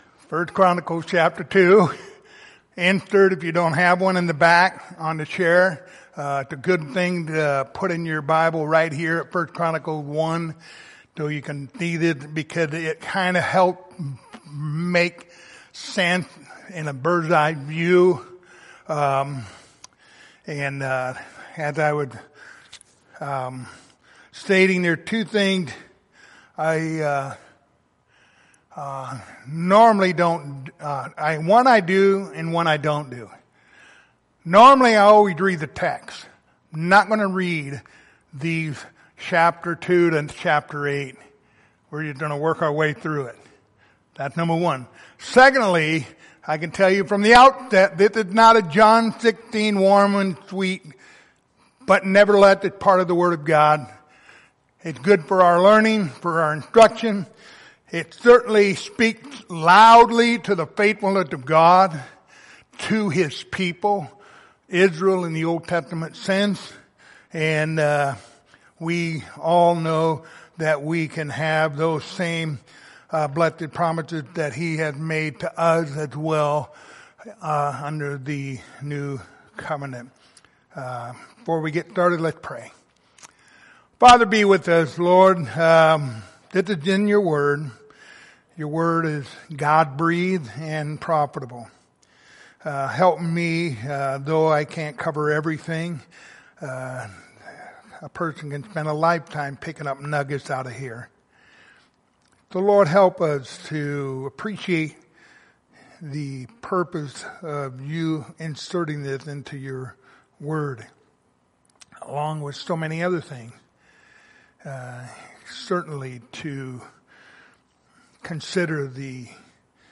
Passage: 1 Chronicles 2-8 Service Type: Wednesday Evening